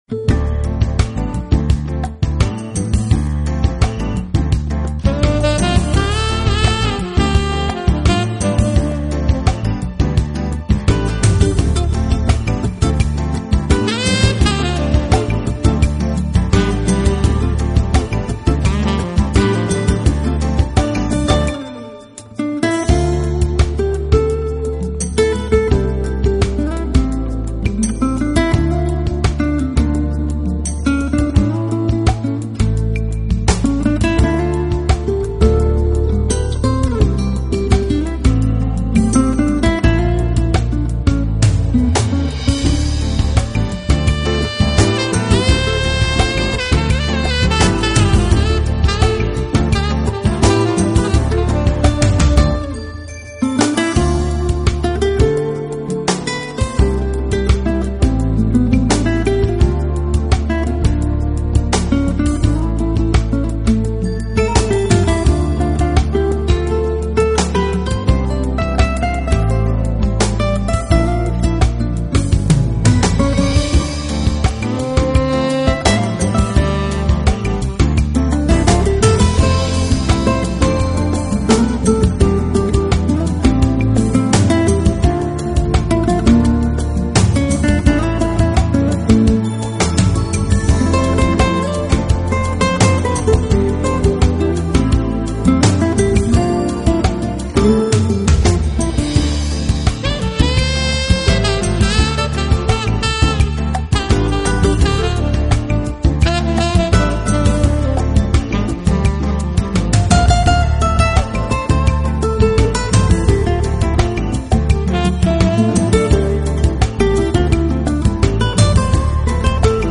这是一张运用了Contemporary和Fusion爵士手法的圣诞音乐精选集，将传统的主打
guitar
tenor saxophone
saxophone, flute
flugelhorn
keyboards